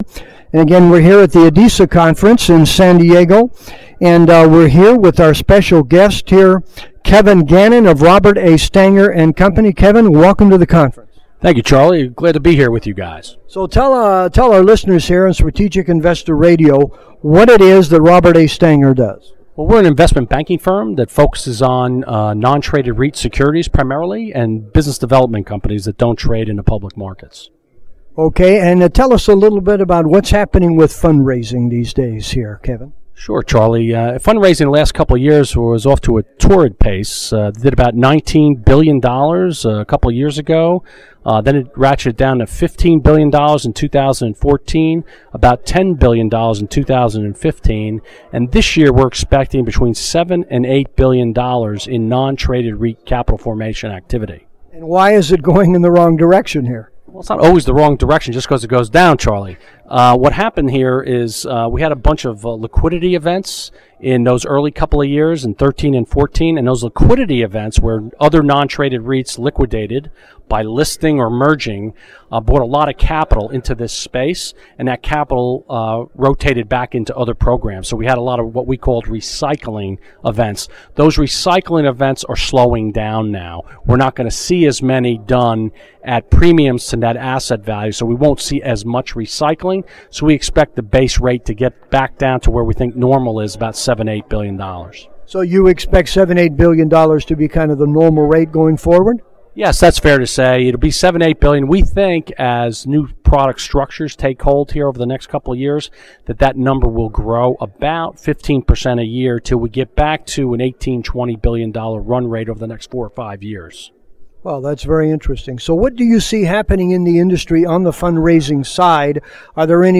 Stanger and Co. is an Investment Banking Firm focused on the Non-Traded REIT and Business Deveopment Company marketplaces. This interview was done at the ADISA Conference in San Diego.